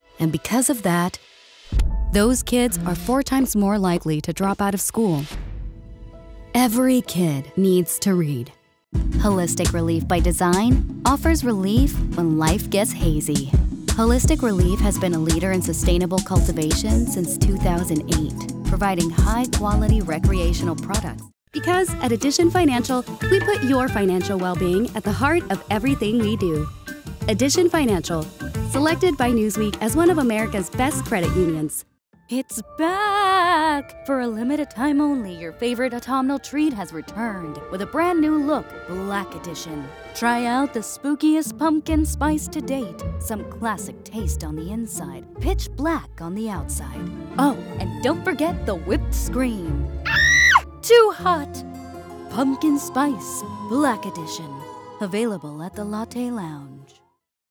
Bilingual Mexican American Texas Based Voice Actor
Demos
Mexican/Neutral
Young Adult